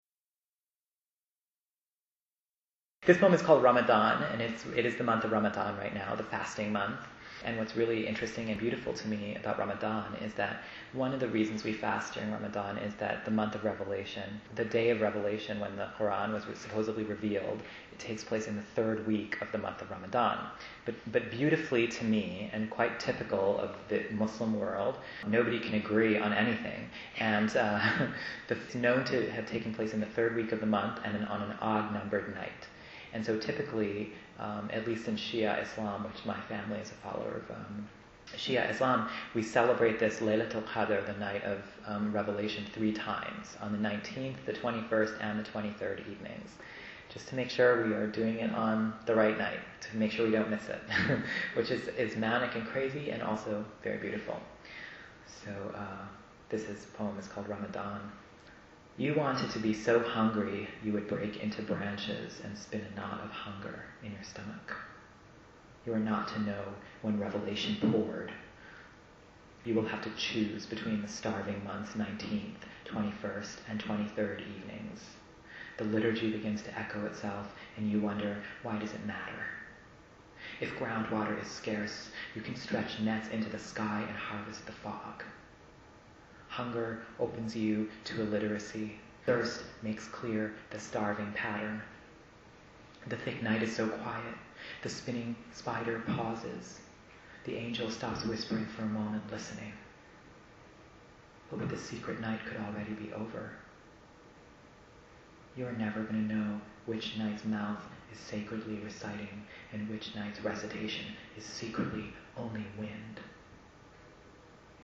Ramadan (live) | Fishouse
Ramadan_live.mp3